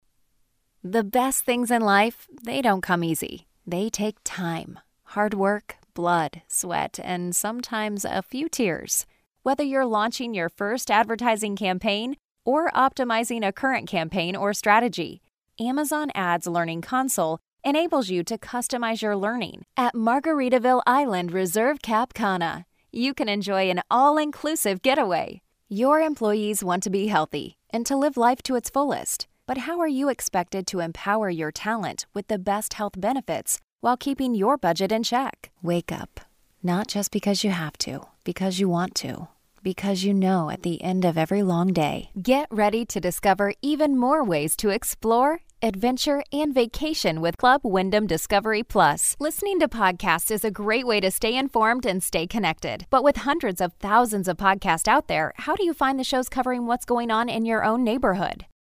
Female
My voice is youthful, upbeat, conversational and relatable.
Corporate
Corporate Narration Demo